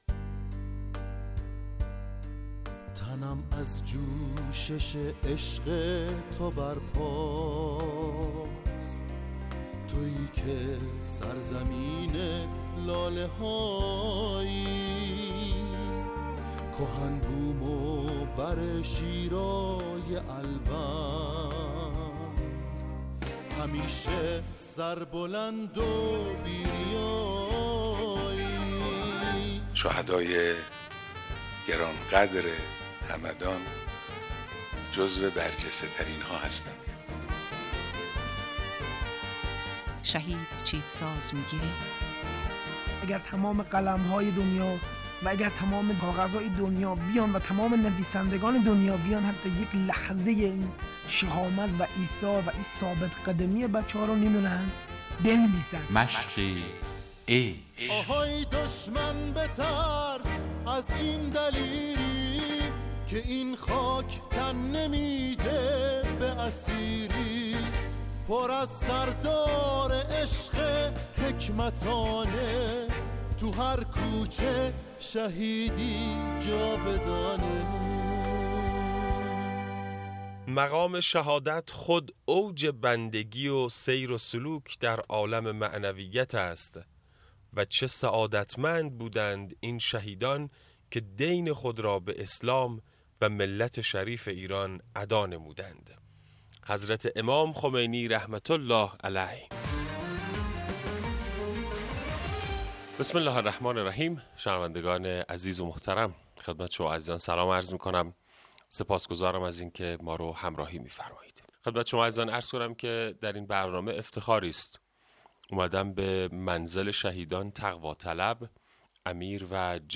گفتگو
در برنامه رادیویی مشق عشق